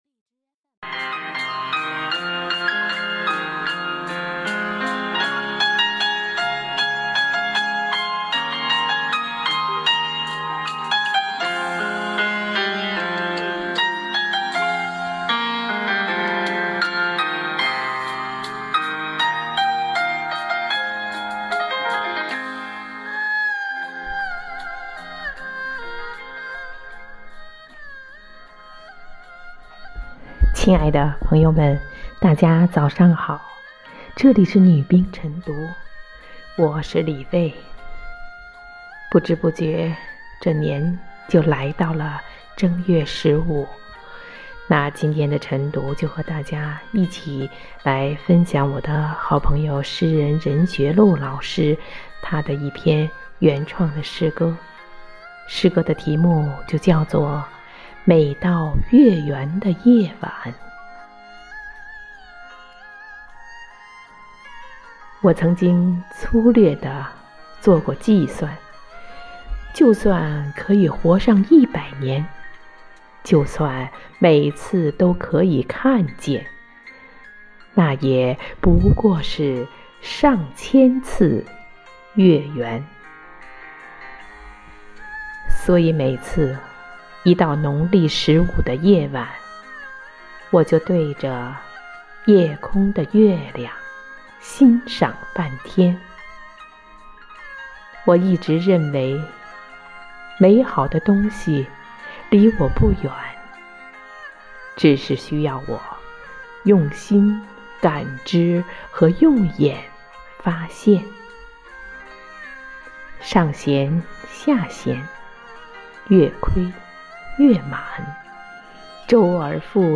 每日《女兵诵读》每到月圆的夜晚